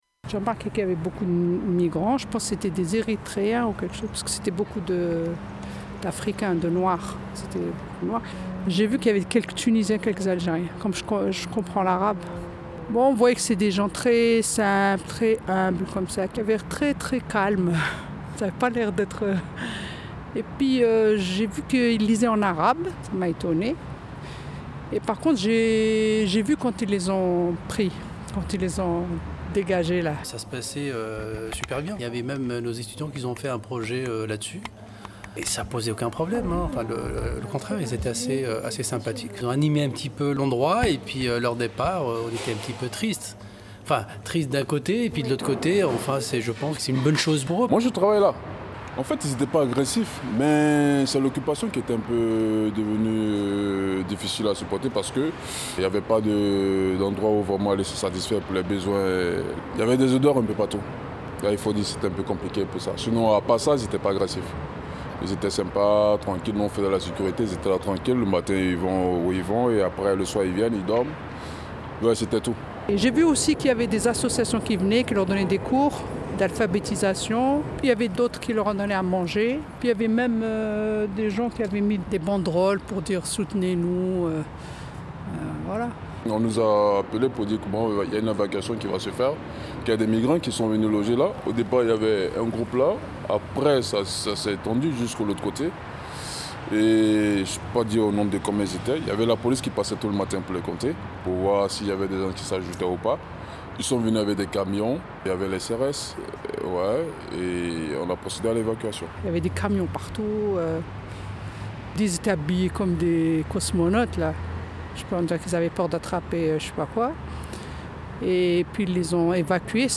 Ces ateliers se sont clôturés sur l'enregistrement d'une émission dans les conditions du direct à l'Espace de Vie Etudiante. Les étudiants se sont intéressés à la question des réfugiés et se sont demandés ce qu'il était possible de faire pour les accueillir dans les meilleures conditions possibles.
Partant de ce questionnement, ils vous proposent d'écouter : Des micro trottoirs réalisés à la Cité de le Mode et du Design et à l'UPMC Des interviews pré-enregistrées avec deux jeunes acteurs bénévoles engagés auprès des réfugiés. Une chronique sur les associations d'aide aux réfugiés Et leurs invités